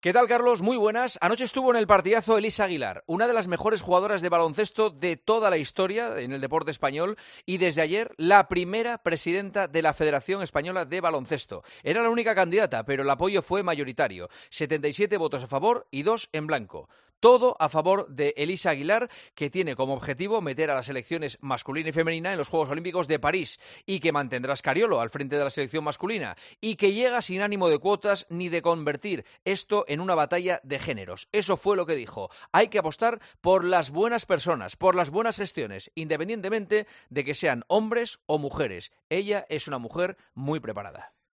Escucha el comentario de Juanma Castaño en Herrera en COPE este martes 3 de octubre de 2023